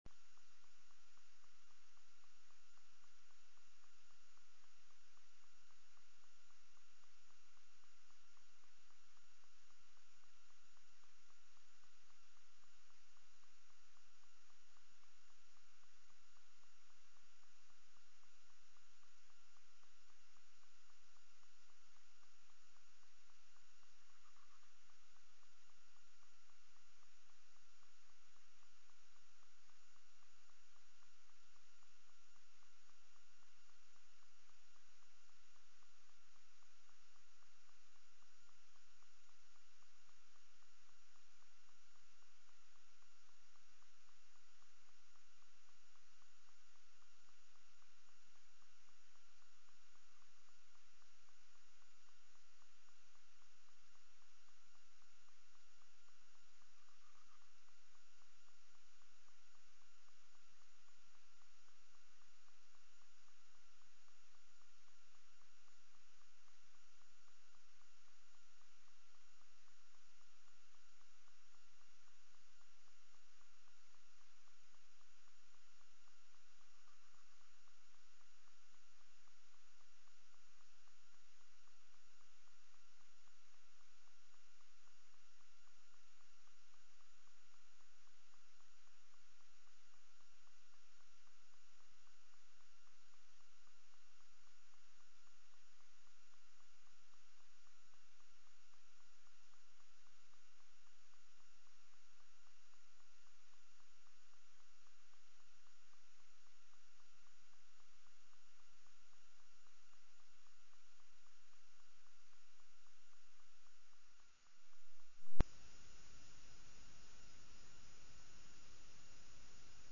تاريخ النشر ٢٧ جمادى الأولى ١٤٢٢ هـ المكان: المسجد الحرام الشيخ: عمر السبيل عمر السبيل صحبة الأخيار